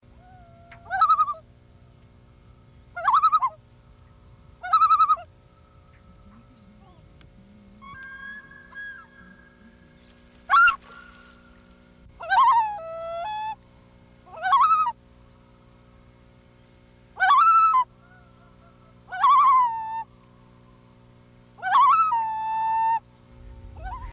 The sample of a loon's cry is just an illustration.
Loons.ogg.mp3